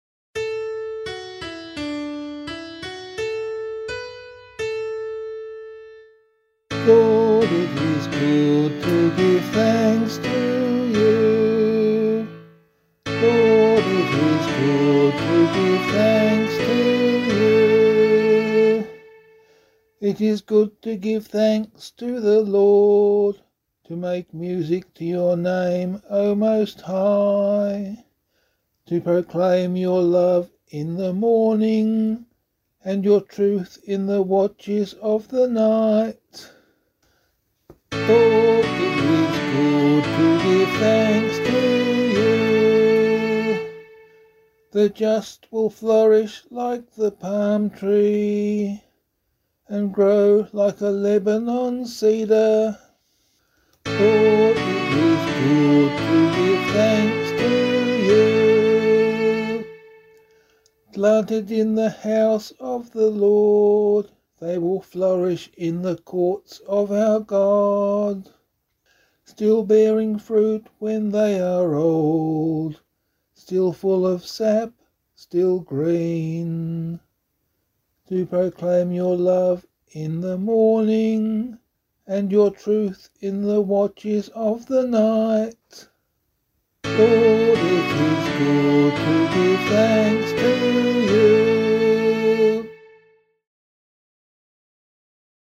042 Ordinary Time 8 Psalm C [LiturgyShare 8 - Oz] - vocal.mp3